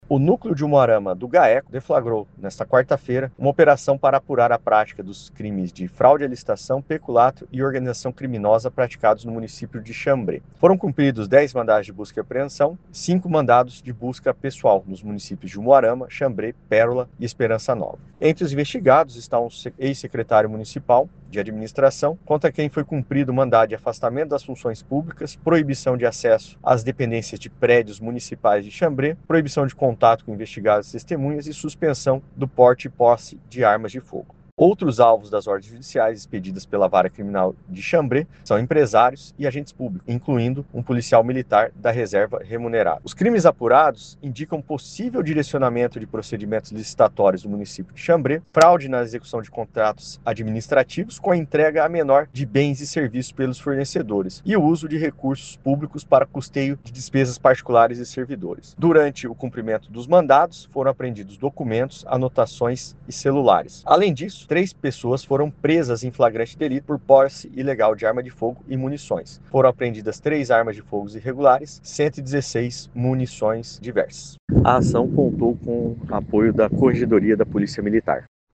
Ouça o que diz o promotor de Justiça.